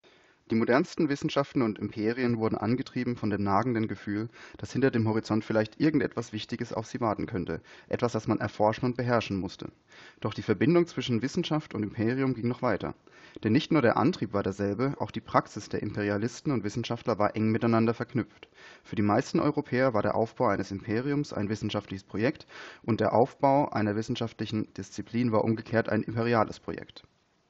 Gesprochenes ist jedoch sehr gut verständlich, wie die Tonaufnahmen verdeutlichen sollten.
Die Aufnahmen wurden jeweils einmal im Original - ergo ohne Nachverarbeitung - und einmal über die Aufnahmefunktion von Pexip aufgezeichnet.
Originalaufnahme Shokz OpenComm
Eingesprochener Text aus dem Buch "Eine kurze Geschichte der Menschheit" von Yuval Noah Harari, aufgenommen mit dem Shokz OpenComm Knochenschallheadset ohne weitere Verarbeitung © VCC